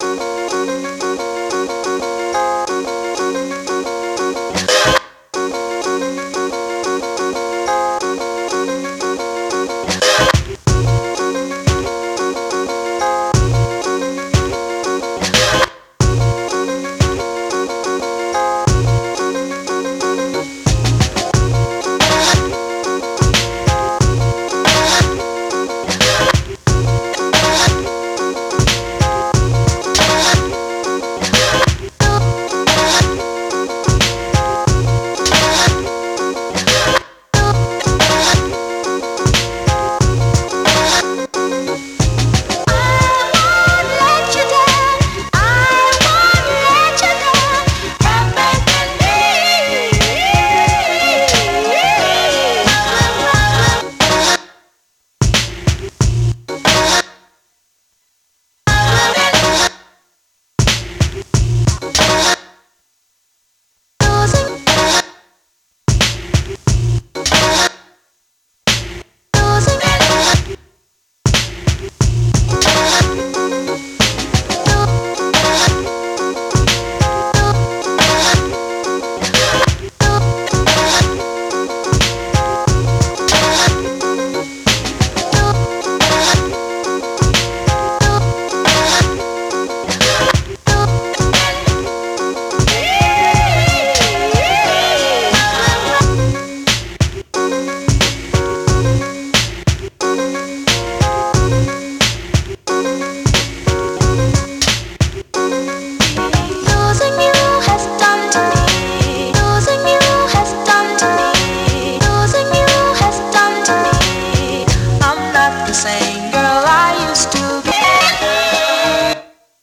Some of the vocal samples contain drum fills, but the core drum work is made with the one-shot kits and I was working more from the angle of trying to make the vocal samples that had drum fills in them fit with my drum patterns rather than sequencing my drum patterns to match the samples with fills.